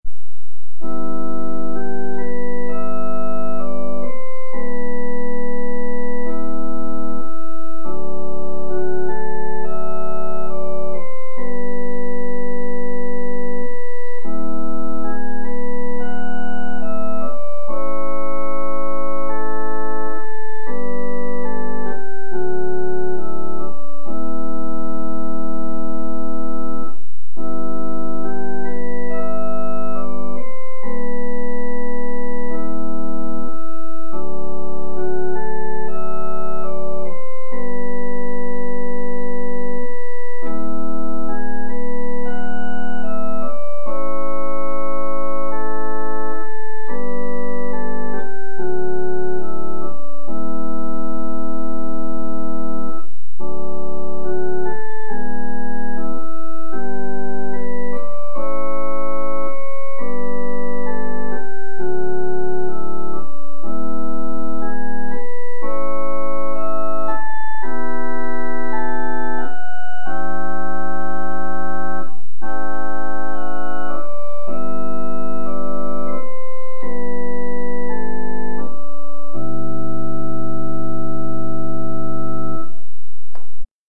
Heimorgel